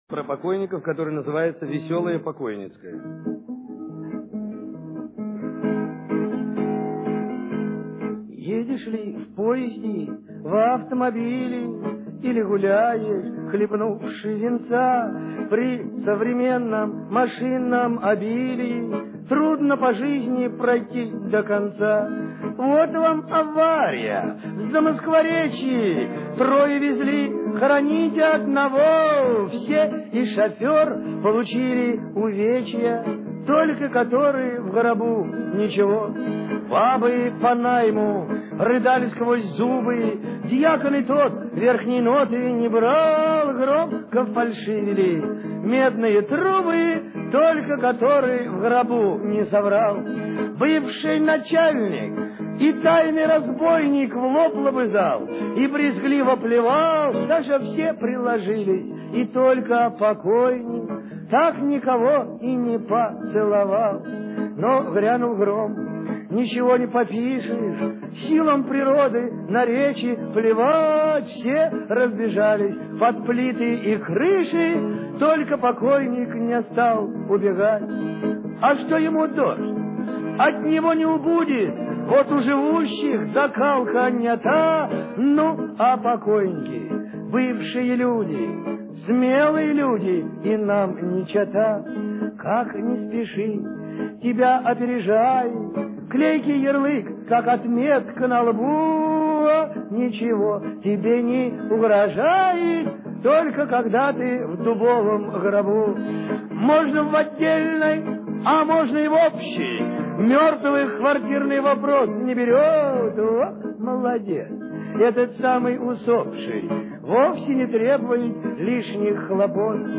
(с концерта)